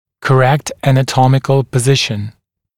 [kə’rekt ˌænə’tɔmɪkl pə’zɪʃn][кэ’рэкт ˌэнэ’томикл пэ’зишн]правильное анатомическое положение